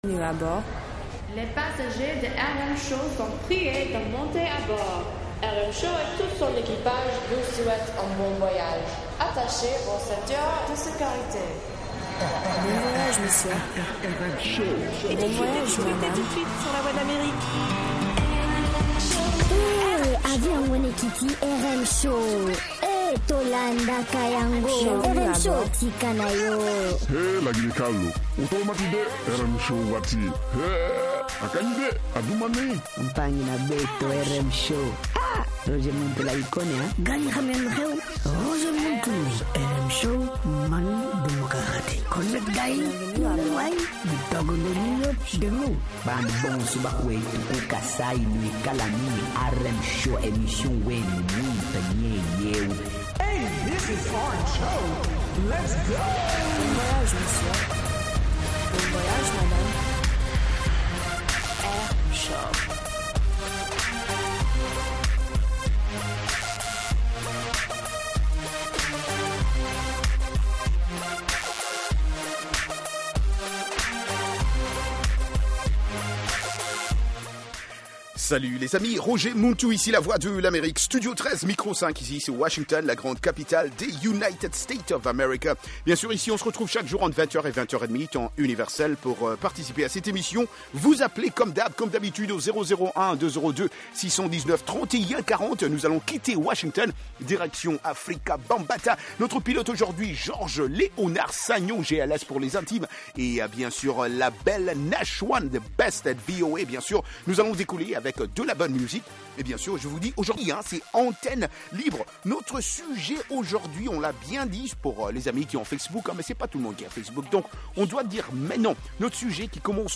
Zouk, Reggae, Latino, Soca, Compas et Afro